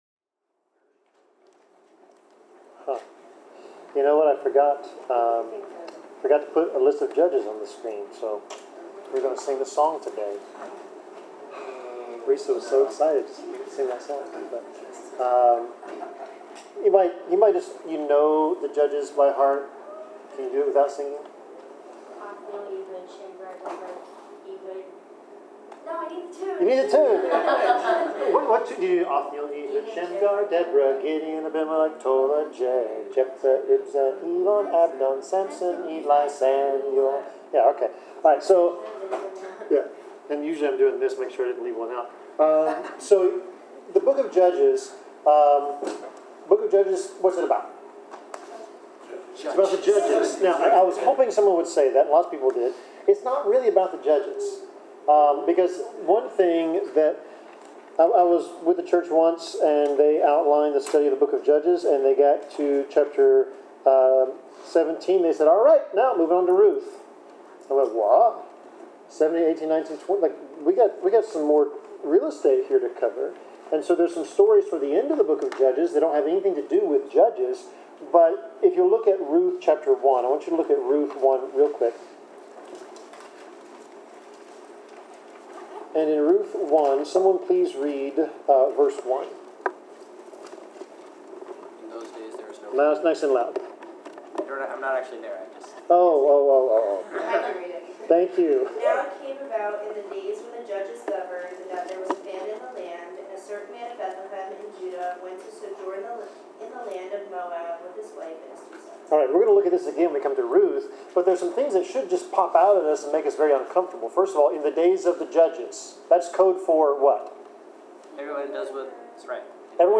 Service Type: Bible Class